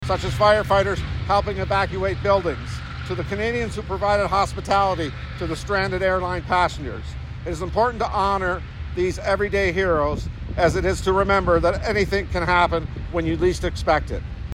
The almost 3,000 people killed by the 9/11 terrorist attacks on New York’s World Trade Centre were remembered Monday morning at a ceremony at the Belleville Fire and Emergency Services headquarters on Bettes Street.